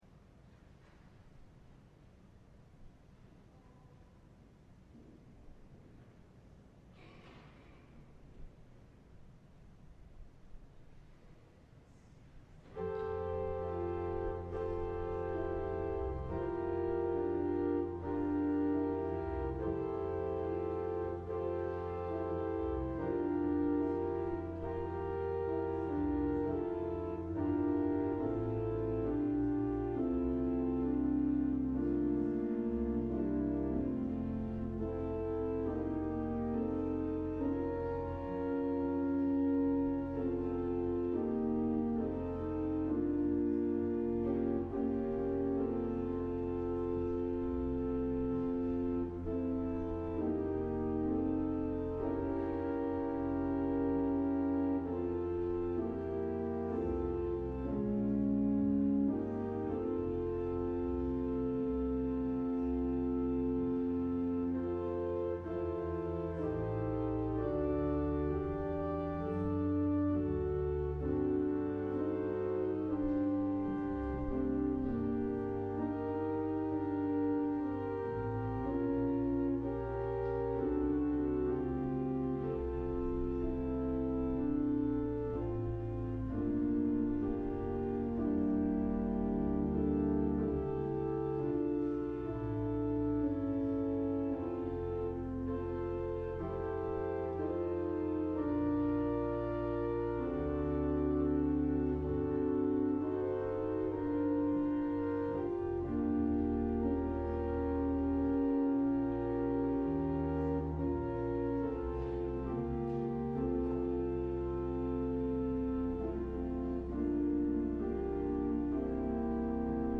LIVE Evening Worship Service - Bones and Breath